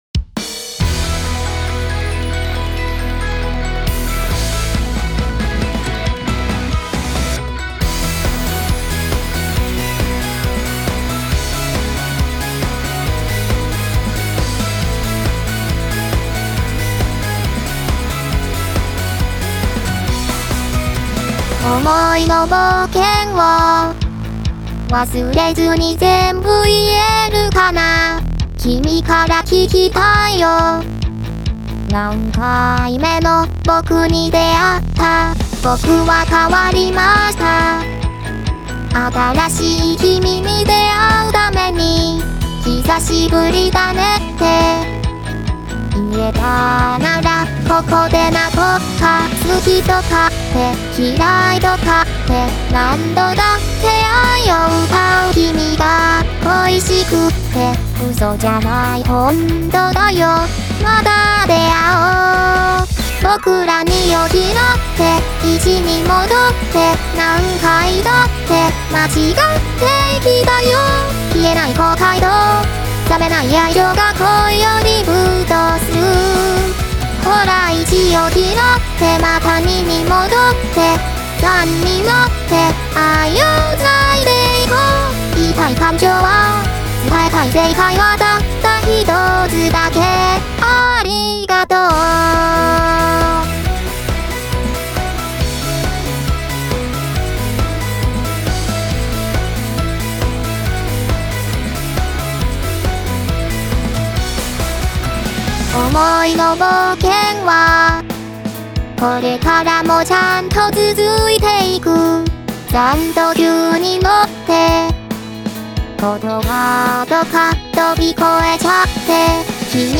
作品类型：翻调曲